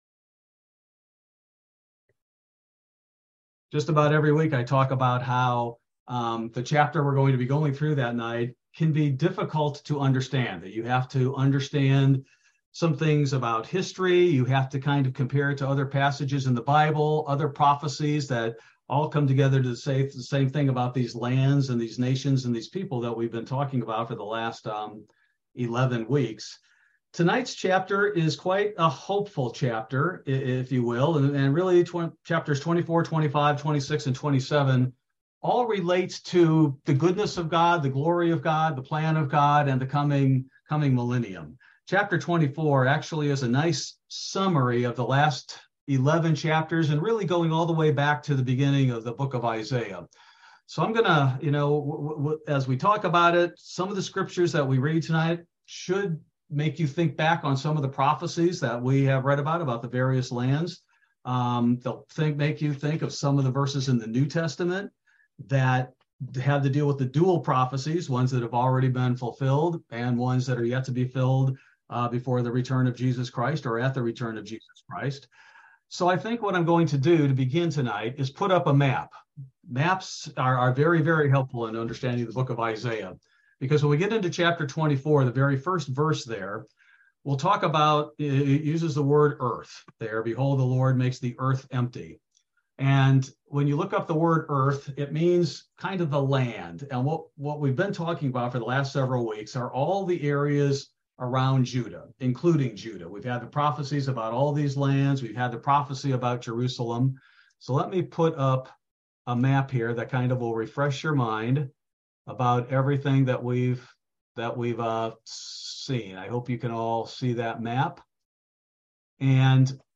Bible Study: January 18, 2023